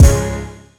Kick19.wav